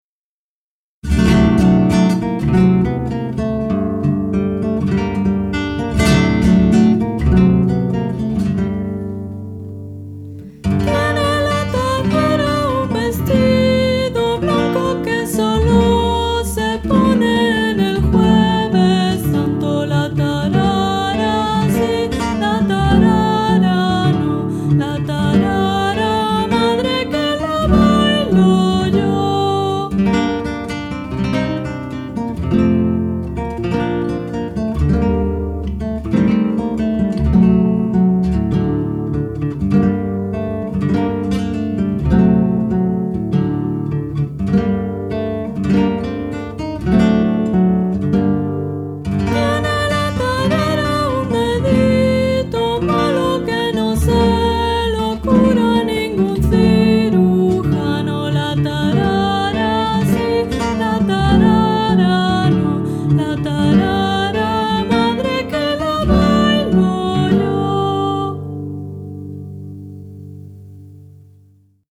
Tarara tradicional